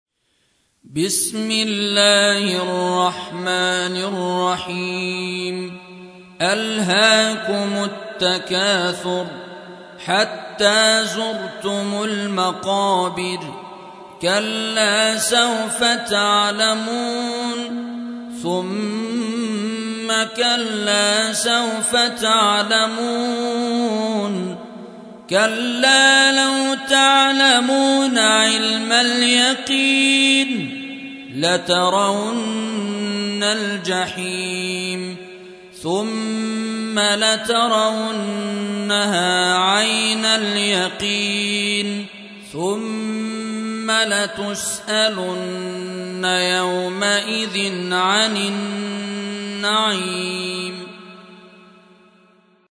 102. سورة التكاثر / القارئ